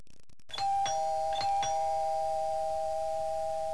doorbell.wav